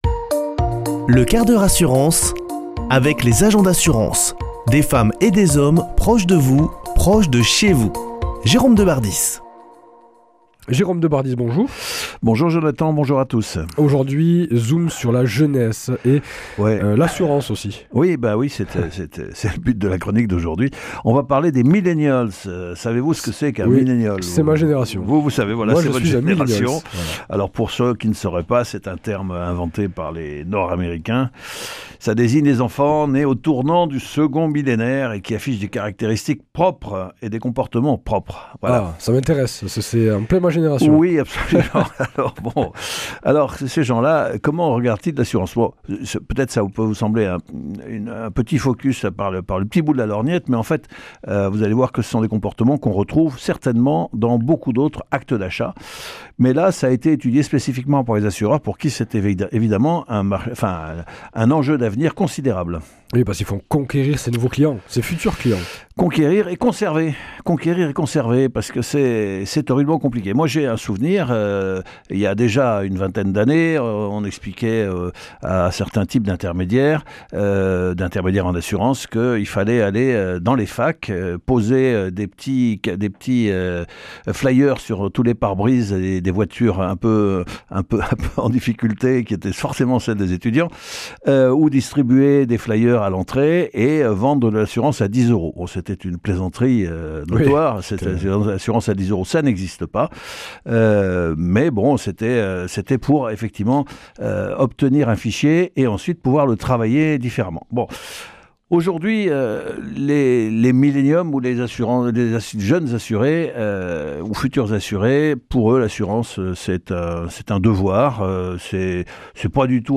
[ Rediffusion ]
Chroniqueur